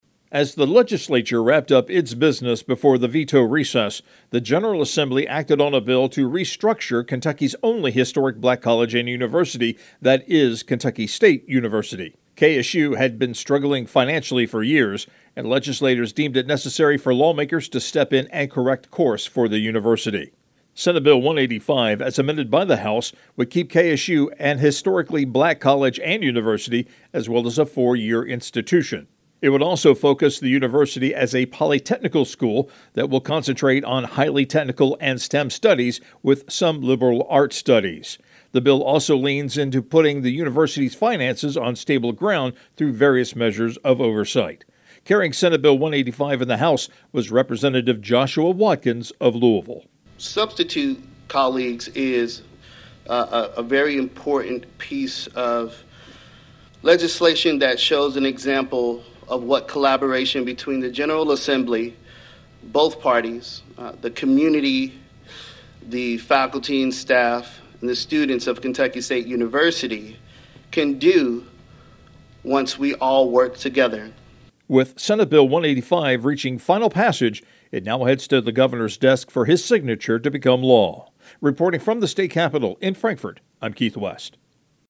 LRC Public Information offers audio feeds over the Internet to help radio news directors obtain timely actualities and pre-produced news spots.